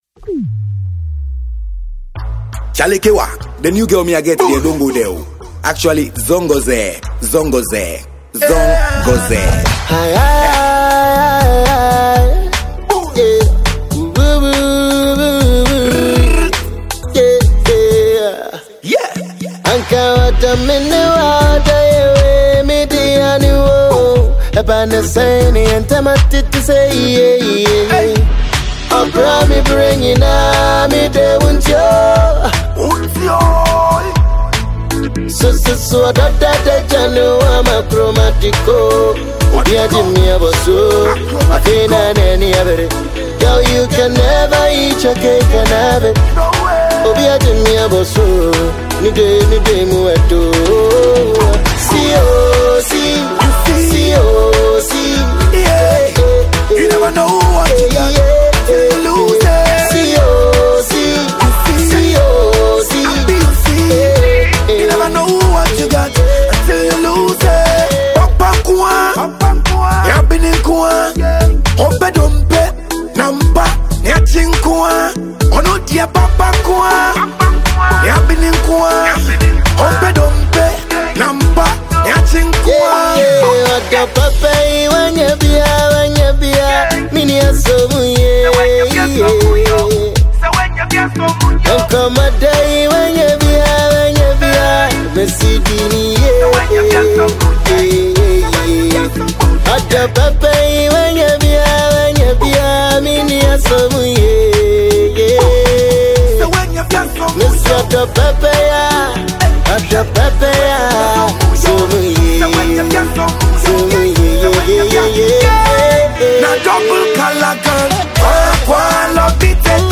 reggae–dancehall